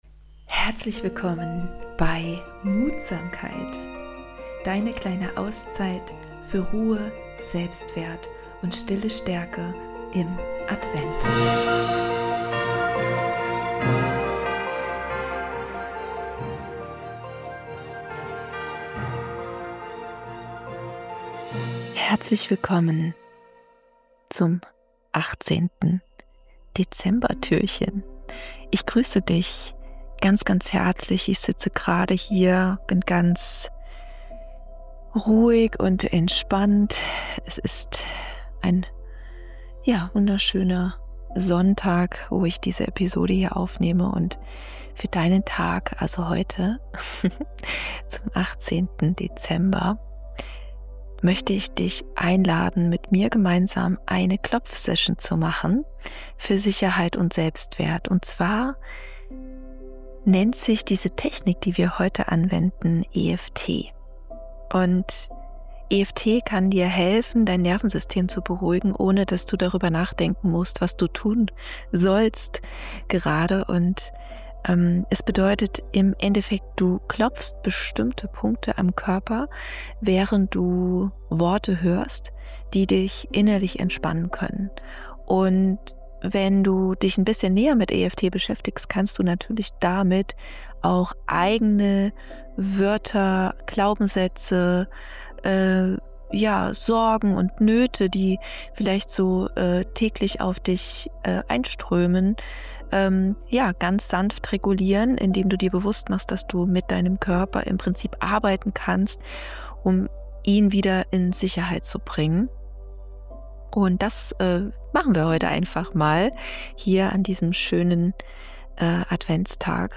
In dieser Adventsfolge machen wir gemeinsam eine EFT-Klopfsequenz für Sicherheit, Selbstwert und innere Ruhe.